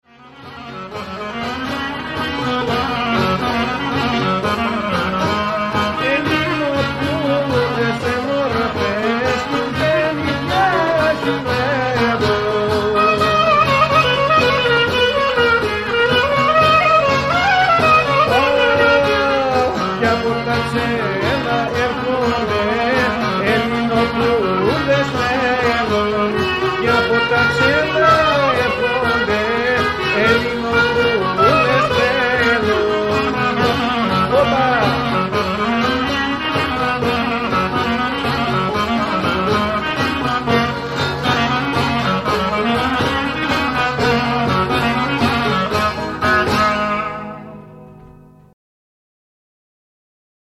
Kalamatianos - This is the Pan-Hellenic dance in 7/8 meter.